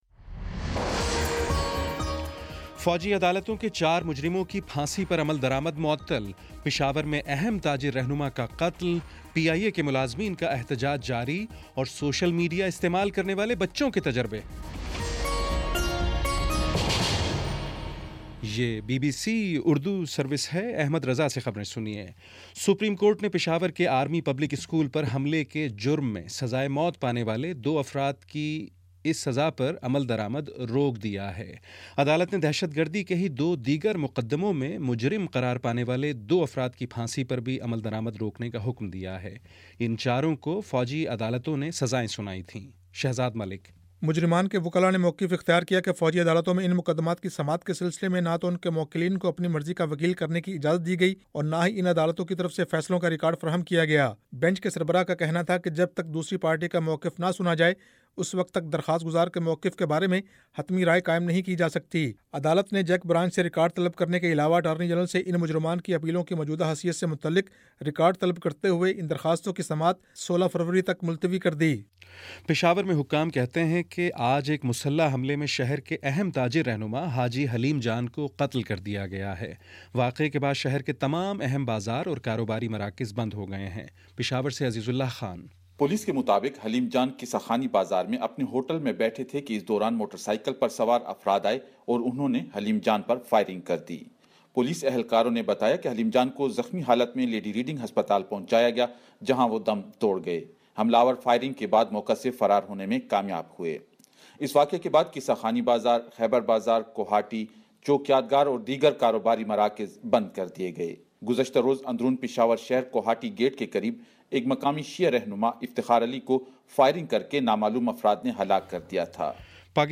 فروری 09 : شام پانچ بجے کا نیوز بُلیٹن